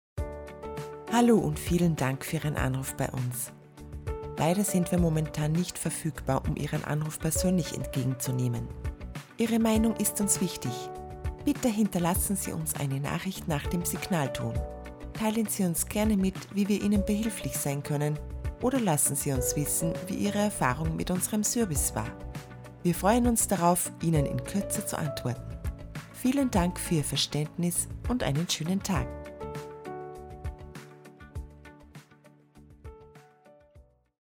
Female
Österreichisches Deutsch, warmherzig, freundlich, charmant, beruhigend, energisch
Phone Greetings / On Hold
Example Of Ivr Recording
1003Anrufbeantworter_IVR.mp3